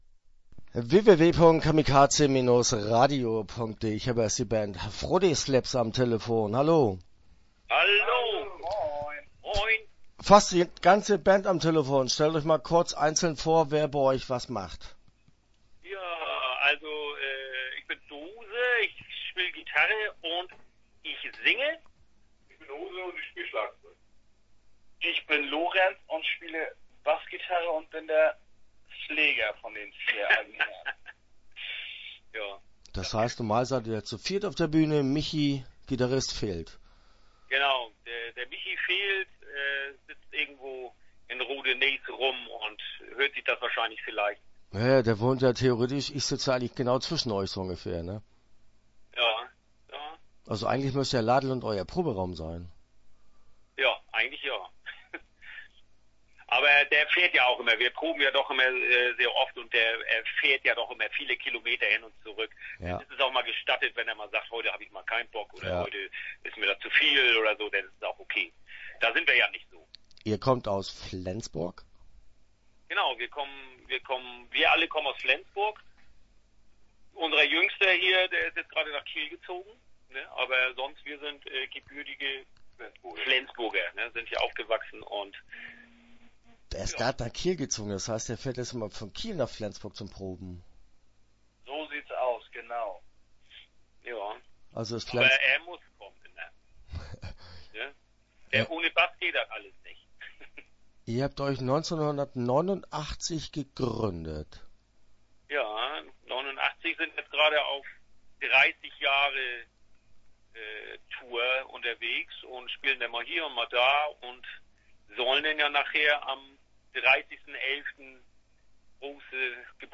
Start » Interviews » Fro-Tee Slips